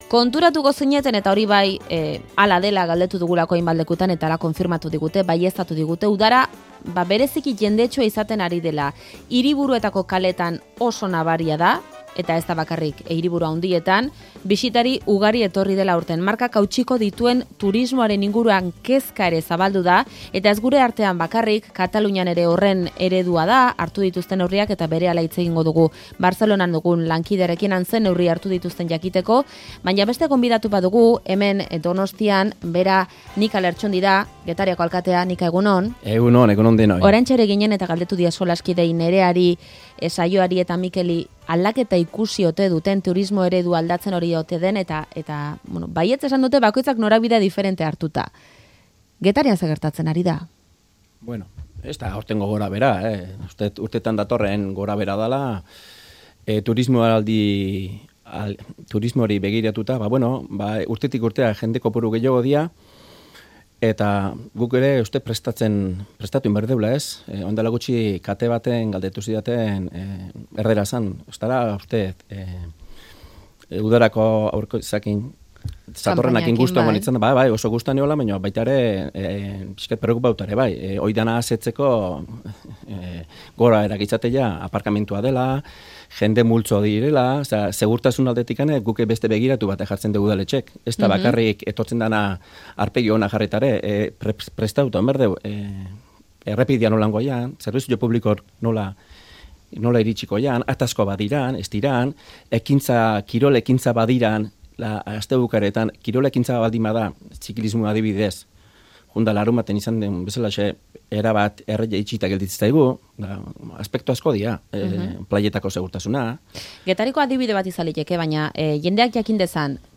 Audioa: Turismo ereduaz aritu gara Albiste Faktorian. Nika Lertxundi Getariko alkatea